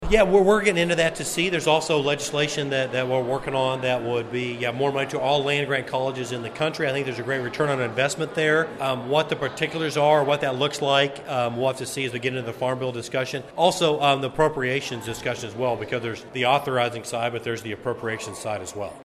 Congressman Tracey Mann met with constituents Tuesday at the Wefald Pavilion in City Park, part of his ongoing listening tour across the Big First district.